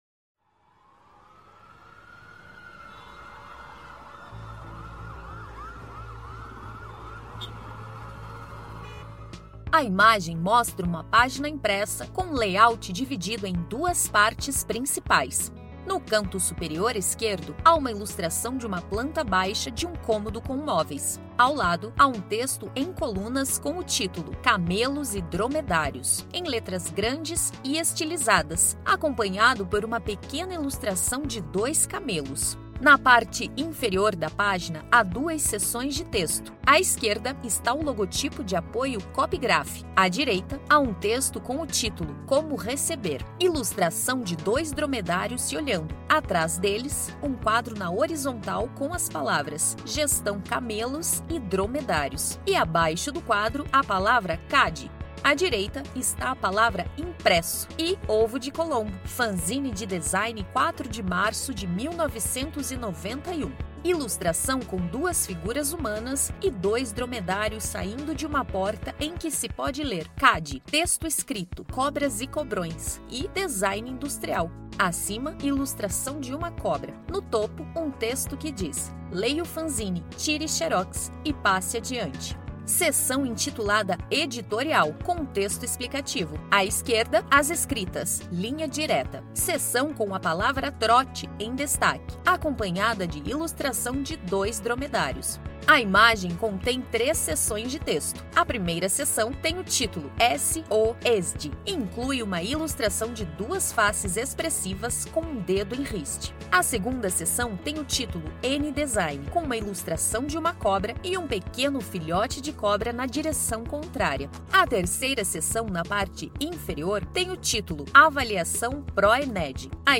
Audiodescrição do Fanzine n° 4